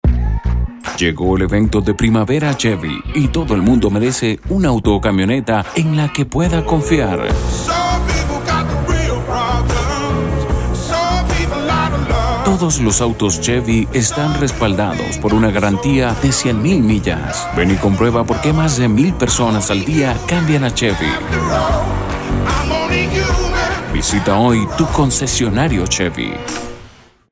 Voz comercial para su negocio o empresa con más de 18 años de experiencia dándole el tono y matiz a sus textos, para así transmitir el mensaje correcto a su futura cartera de clientes.
spanisch Südamerika
Sprechprobe: Werbung (Muttersprache):